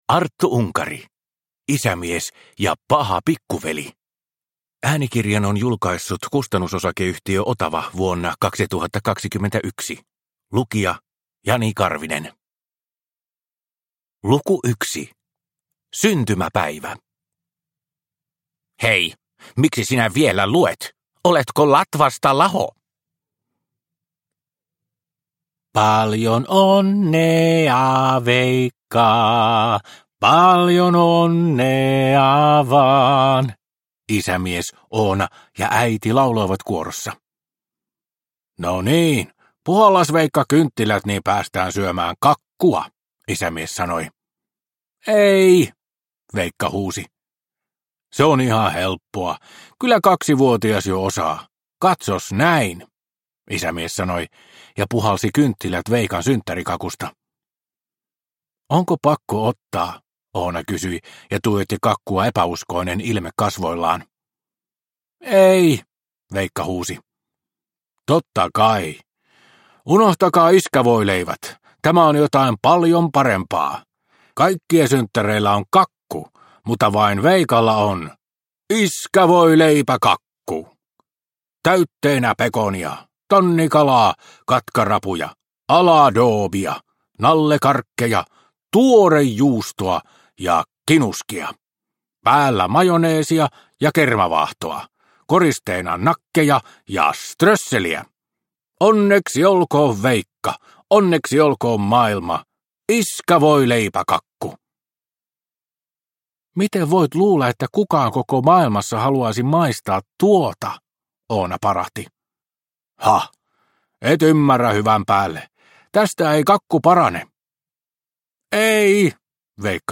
Isämies ja paha pikkuveli – Ljudbok – Laddas ner